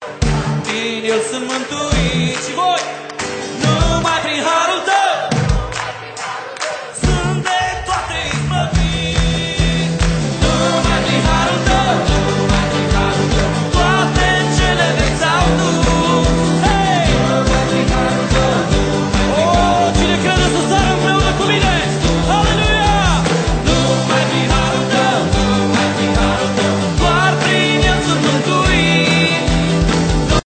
Album de lauda si inchinare inregistrat live